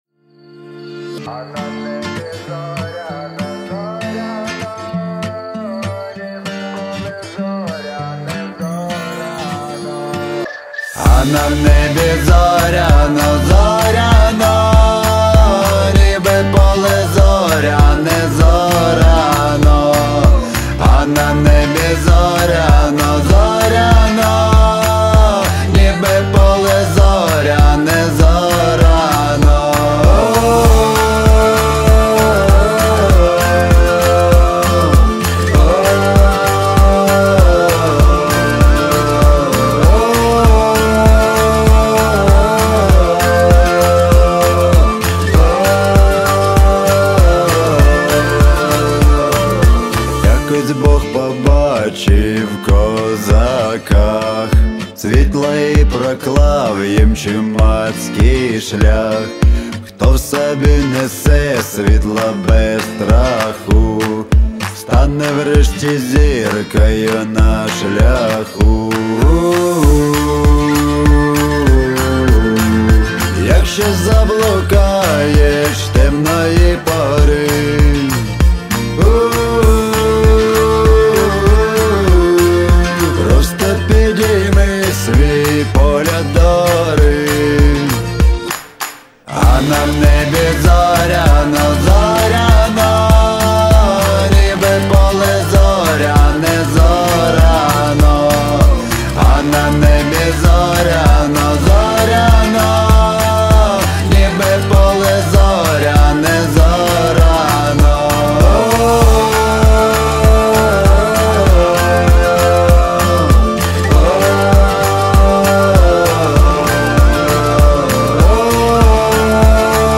• Жанр: Folk